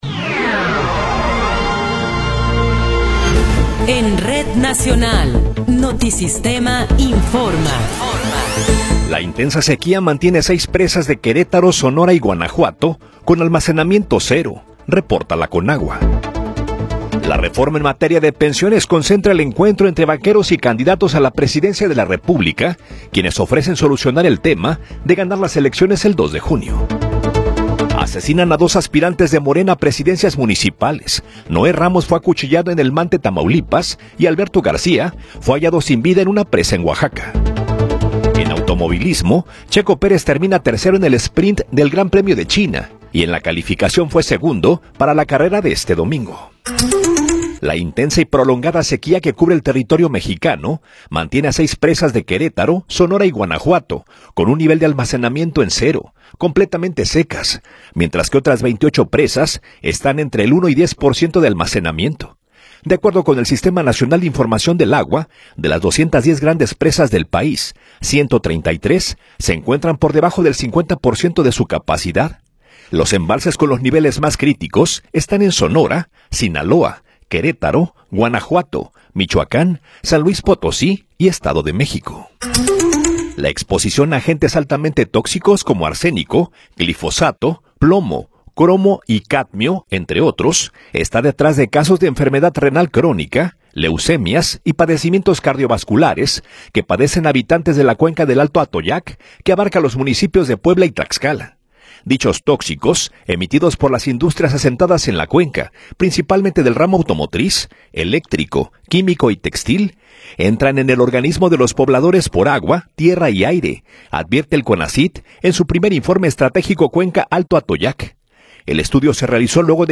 Noticiero 8 hrs. – 20 de Abril de 2024
Resumen informativo Notisistema, la mejor y más completa información cada hora en la hora.